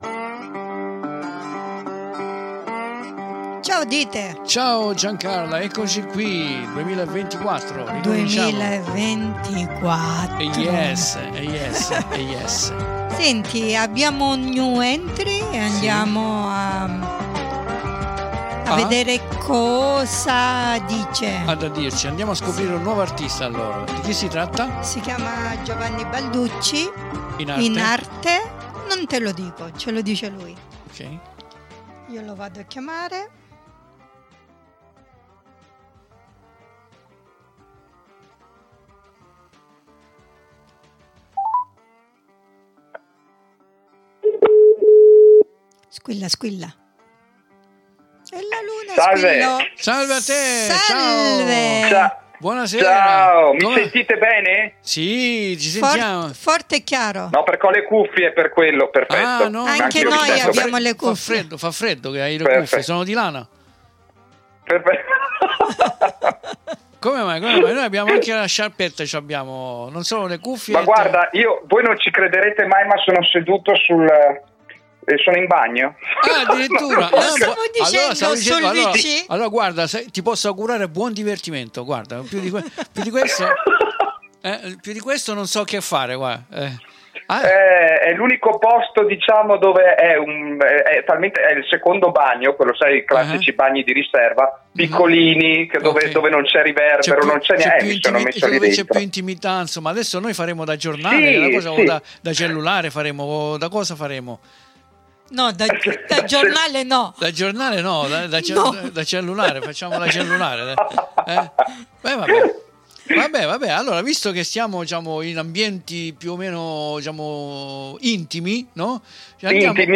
NOI SIAMO BEN LIETI DI INTERVISTARE PERSONE GRANDI, DIETRO L'ARTISTA!